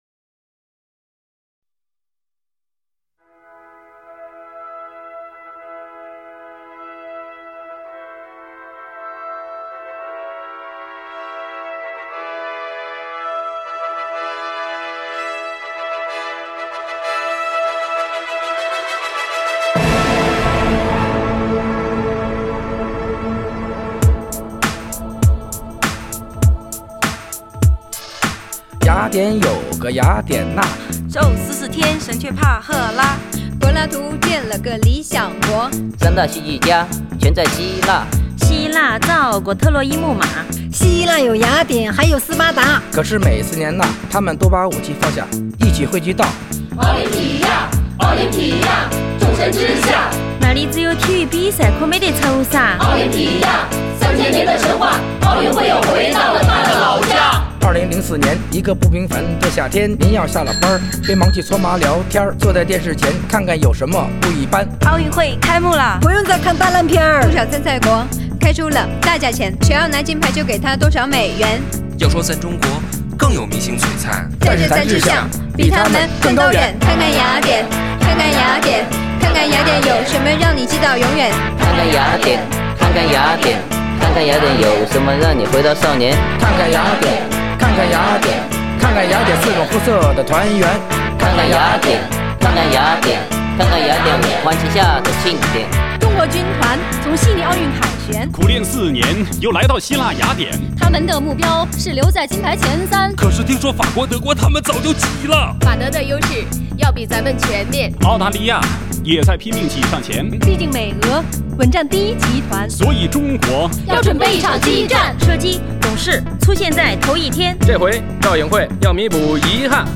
想起来小学上课时朗读课文的情景。